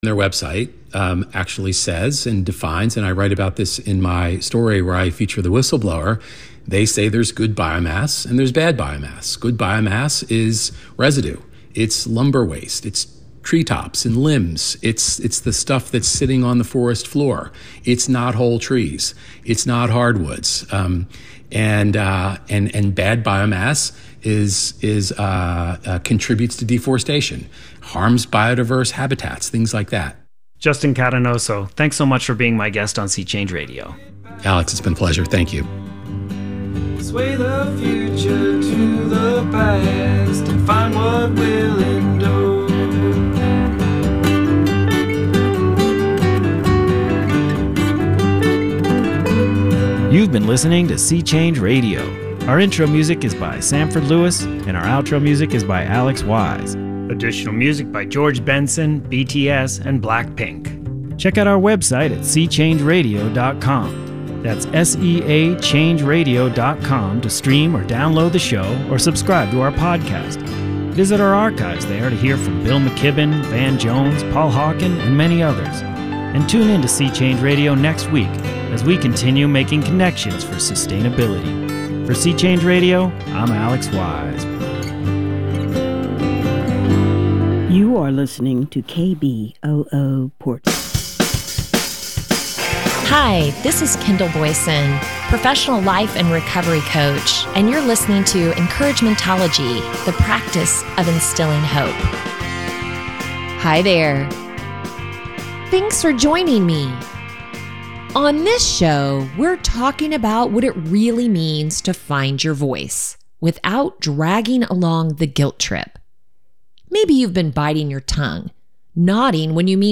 11:30am to 12:00pm A radio show about visual art.